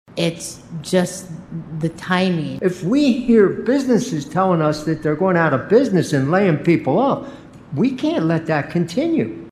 Here are commissioners Quianna Decker and Don Cooney.